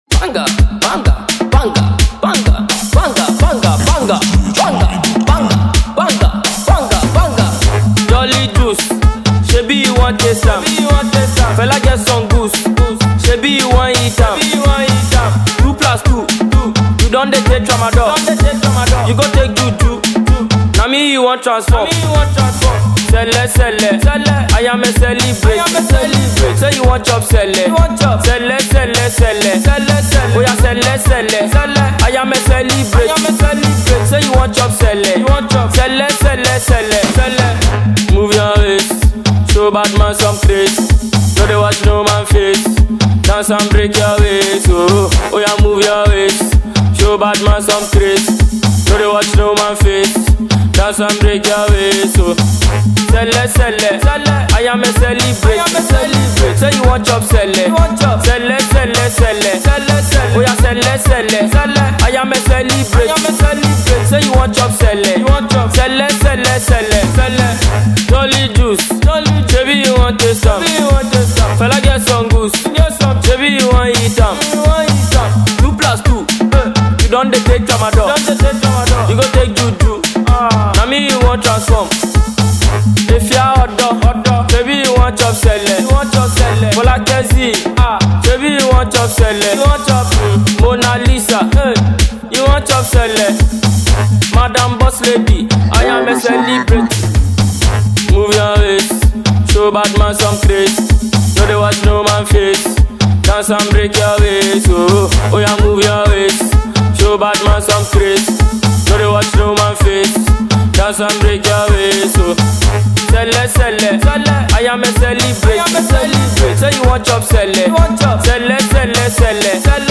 Fast Rising Ghanaian Afrobeats and Dancehall artist